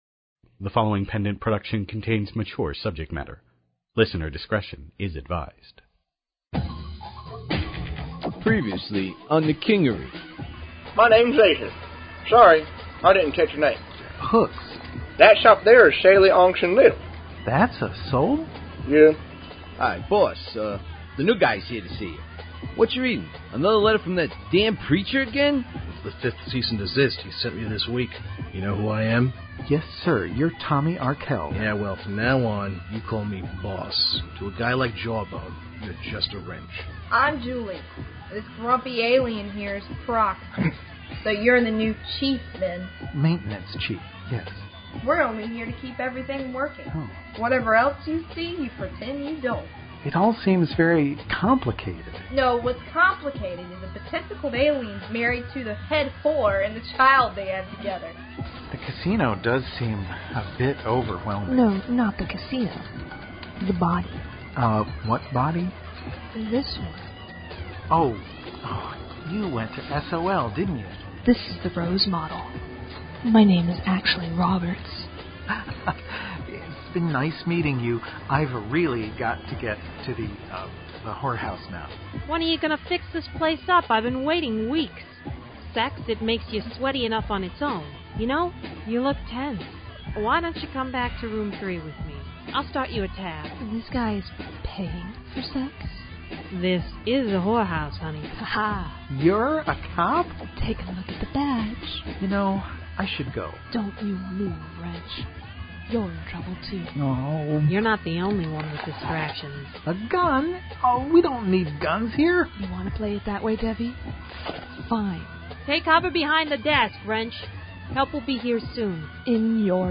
Ok, so a while back I mentioned something about find a cool fun podcast that does old timie radio style dramas of super heros and yadda, right?
Neve will make her debut in episode #15 I have a bit part of a mafioso monosyllabic moll, Major, in The Kingery in episode #2 and soon #7 I've also had the pleasure of playing a British Teen about to be over her boyfriend ( #3 ) and a Cranky Latina (not type cast at all, no?) ( #4 ) in Seminar : a series of short one act plays.